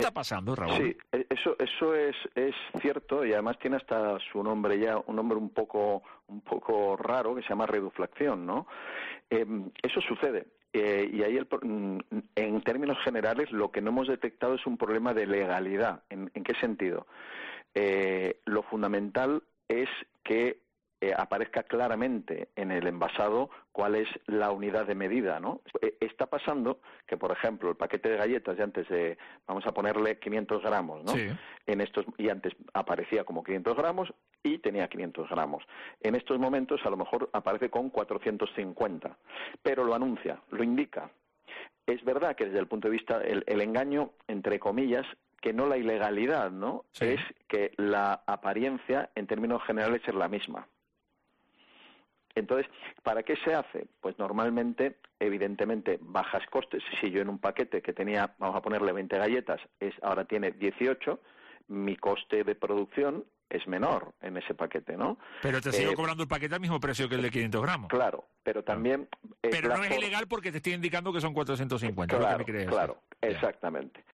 ha dado algunos consejos hoy en La Mañana de COPE Tenerife para combatir esta situación.